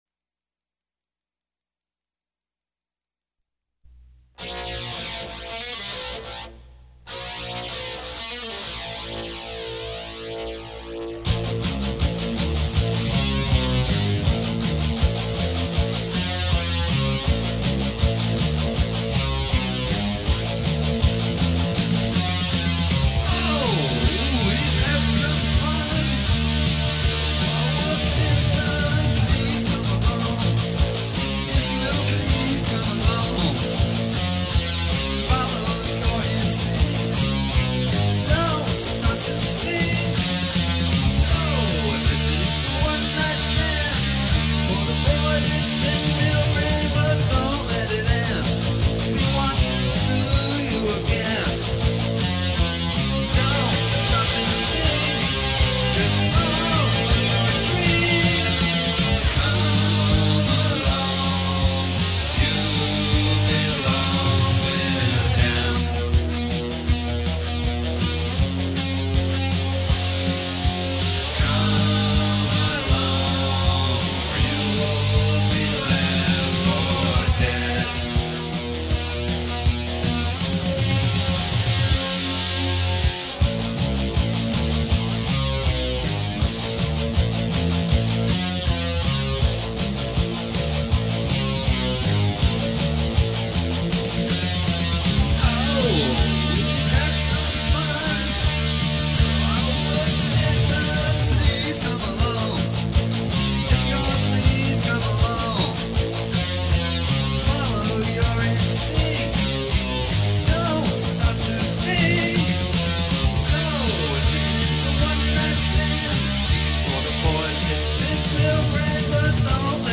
Amps - Rock Man X100 for Guitar, Bass, Vocals
REC - Fostex A-80 8-track and Teac M-208 Mixing Console